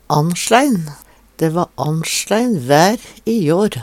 annsjlein - Numedalsmål (en-US)